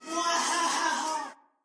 惊讶
描述：惊讶，哇一声。
声道立体声